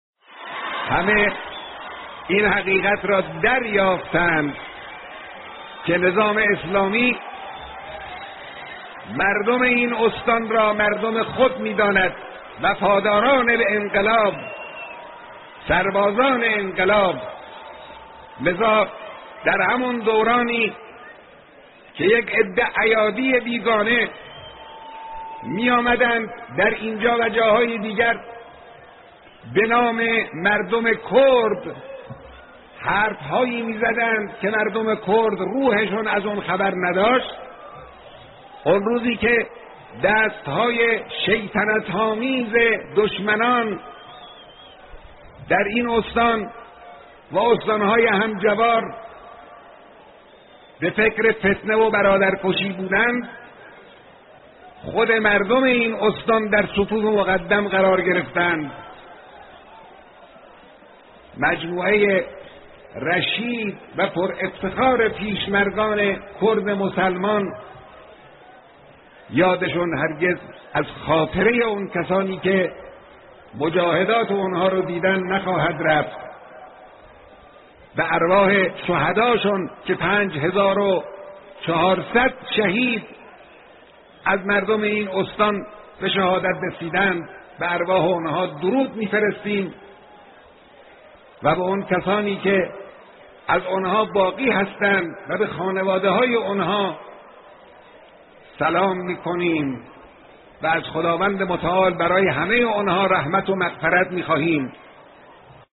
گزیده بیانات رهبر انقلاب در دیدار عمومی/مردم کردستان